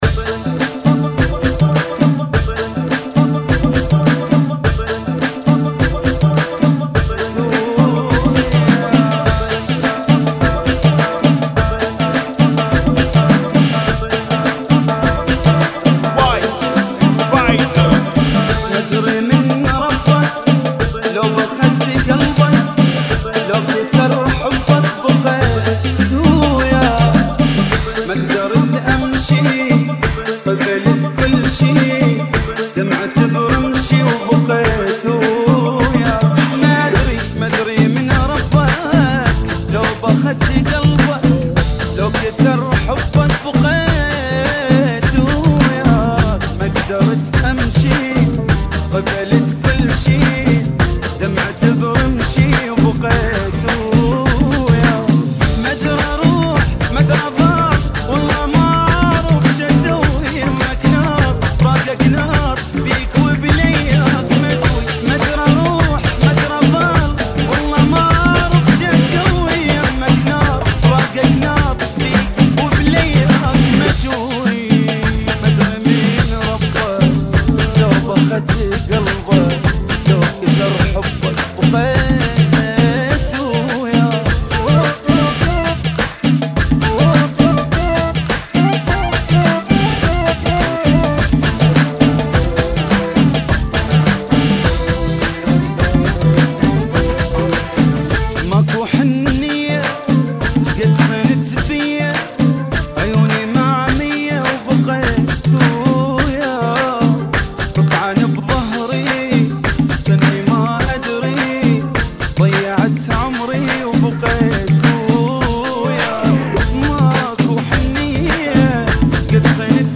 Funky [ 104 Bpm ]